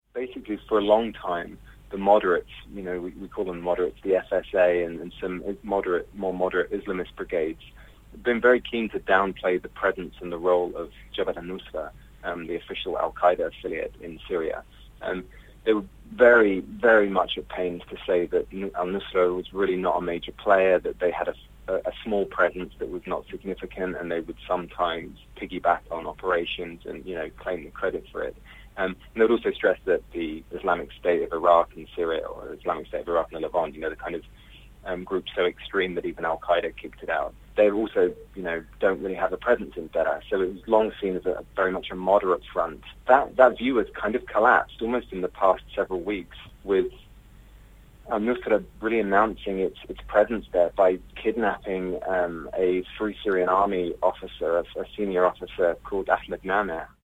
reports from Lebanon